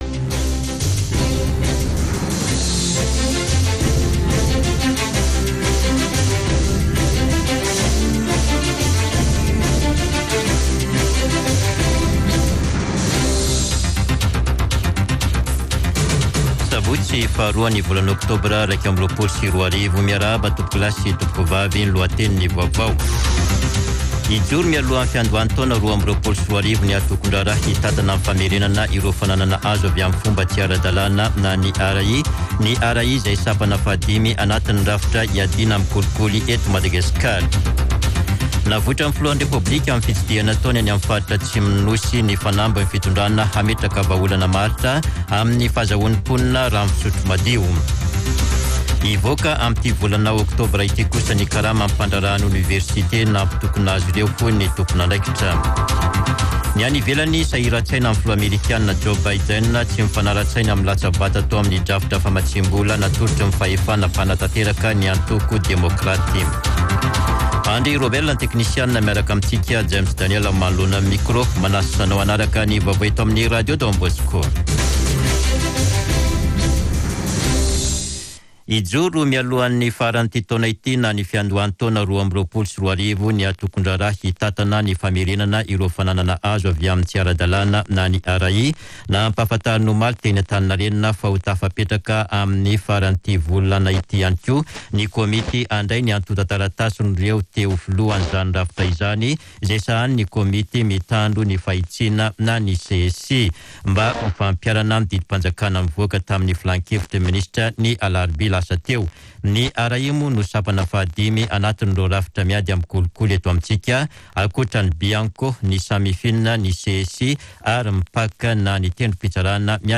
[Vaovao maraina] Sabotsy 02 oktobra 2021